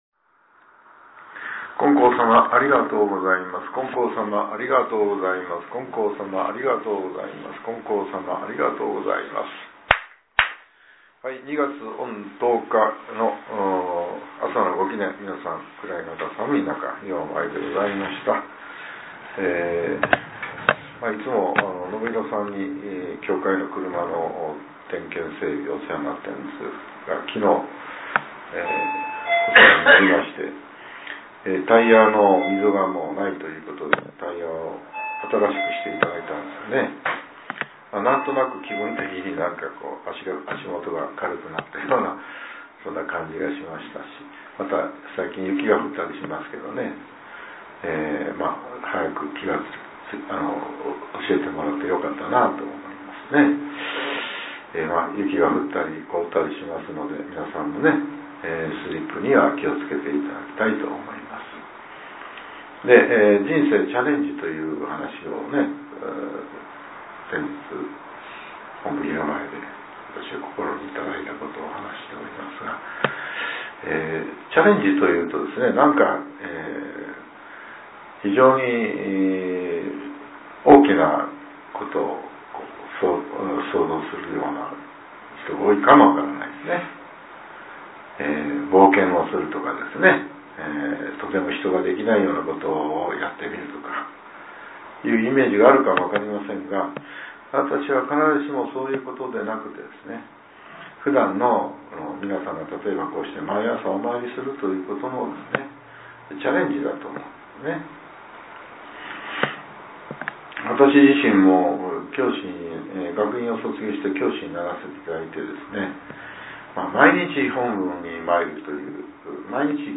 令和８年２月１０日（朝）のお話が、音声ブログとして更新させれています。